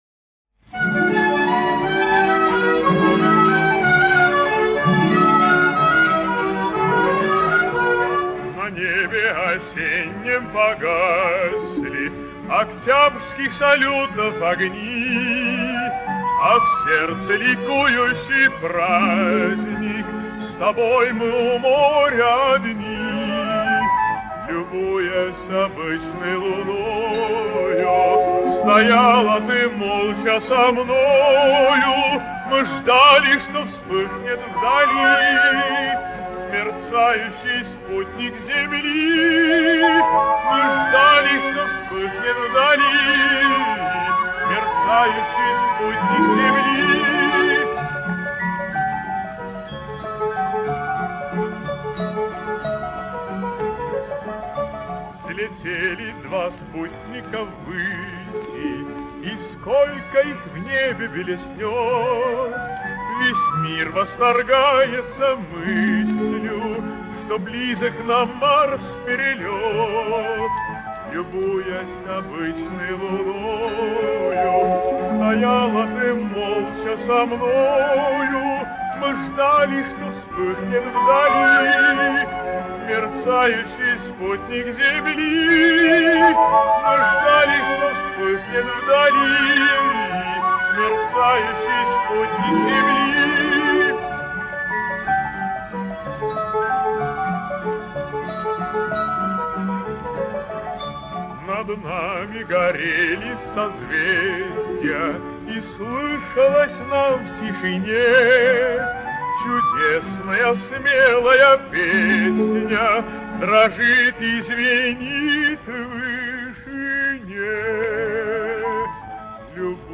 Эстонский певец (баритон), народный артист СССР (1960).